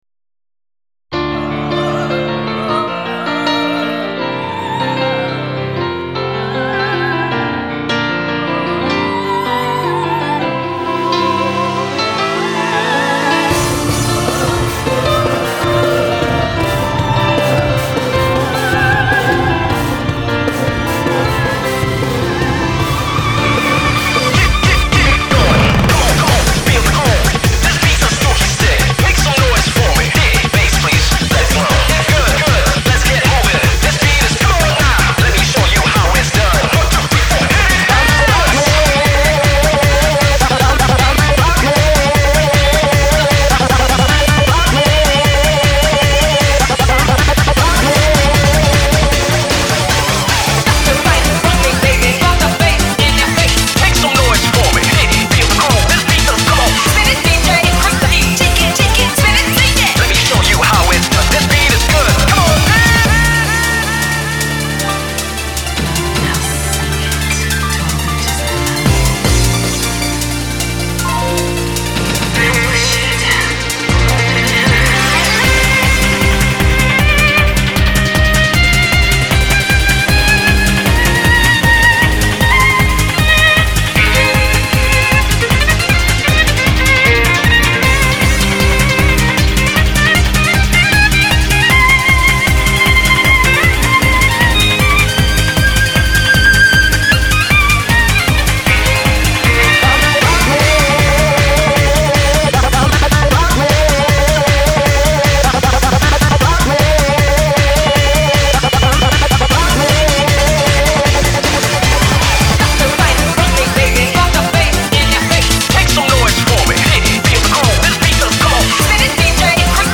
BPM78-155
Audio QualityPerfect (Low Quality)
RAVE